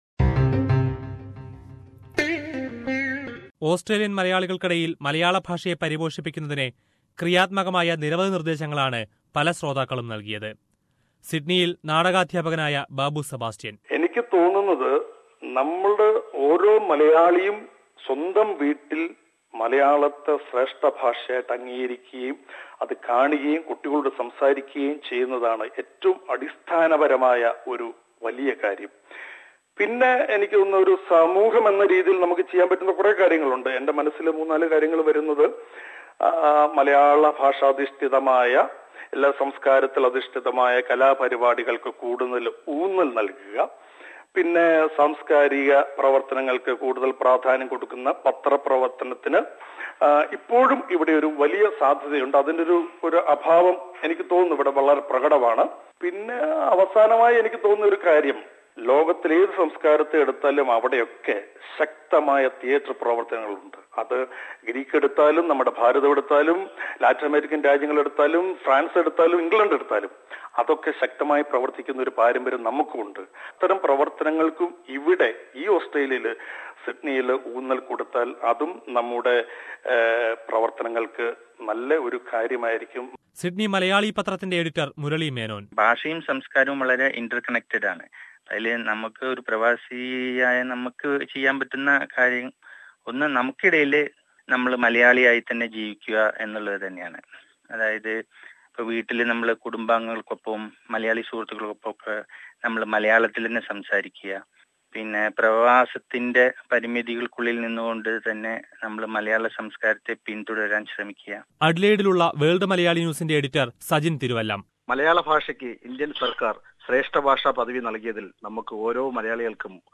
What contribution can Australian Malayalees give to the language? Reactions from various people around Australia